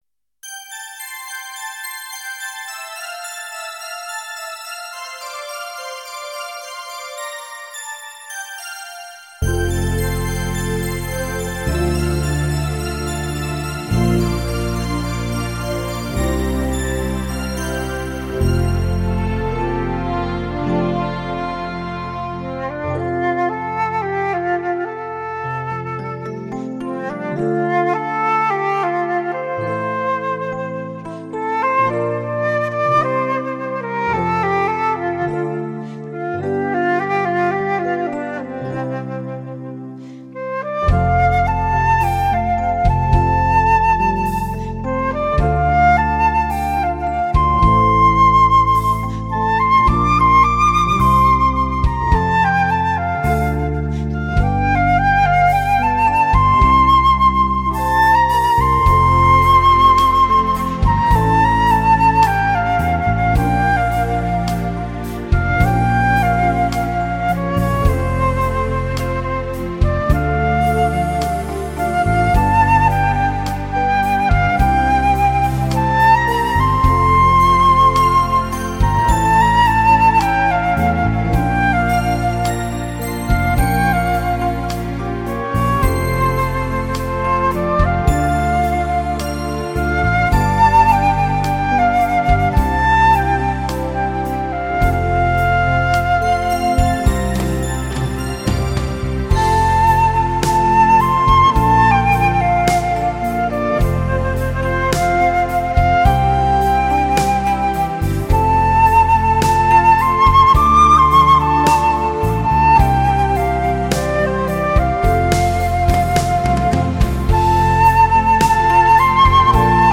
笛声优美通泰，润滑透亮
长笛低音域的音色富丽甘美，高音域则光辉而艳丽。